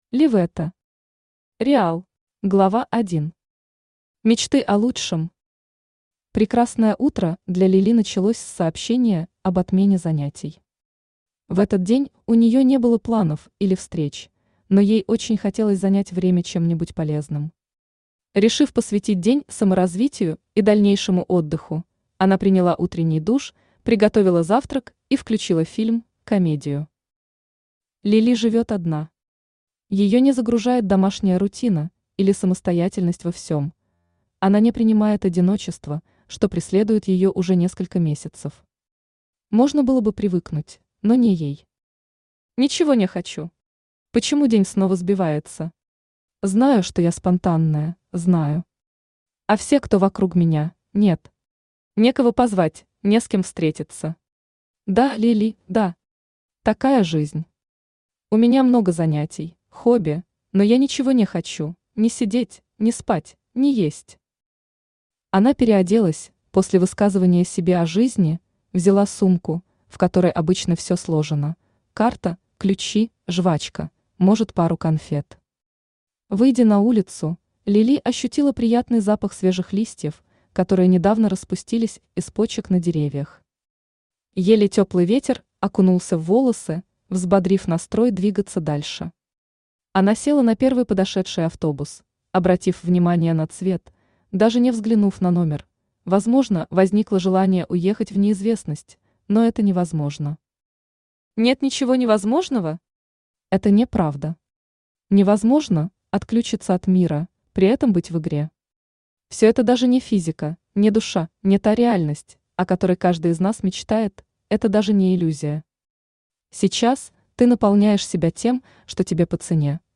Аудиокнига Реал | Библиотека аудиокниг
Aудиокнига Реал Автор liveta Читает аудиокнигу Авточтец ЛитРес.